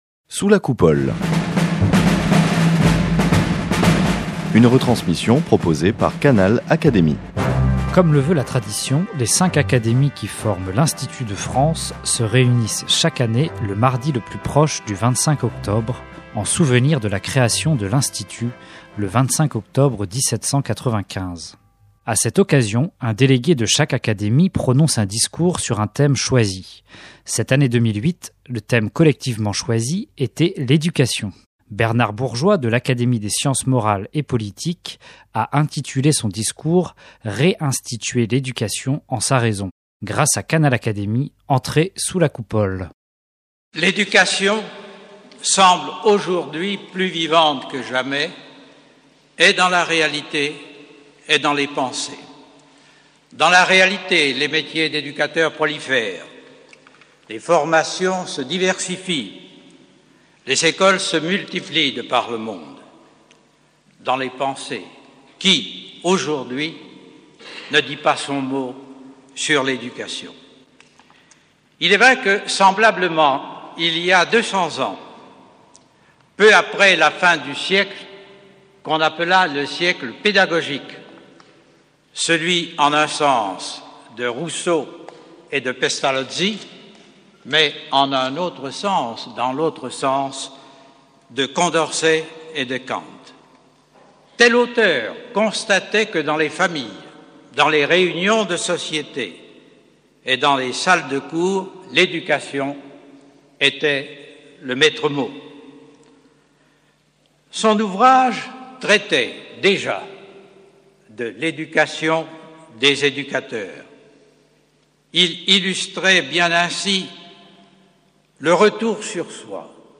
Comme le veut la tradition, les cinq Académies qui forment l’Institut de France se réunissent chaque année le mardi le plus proche du 25 octobre, en souvenir de la création de l’Institut (25 octobre 1795). Pour leur rentrée 2008, les cinq académies avaient choisi pour thème l’éducation.